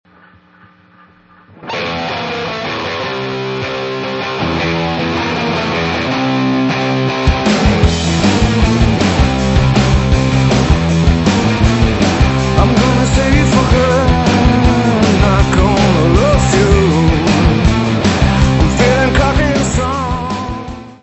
bass
drums, percussion.
hammond b3, fender rhodes
: stereo; 12 cm
Music Category/Genre:  Pop / Rock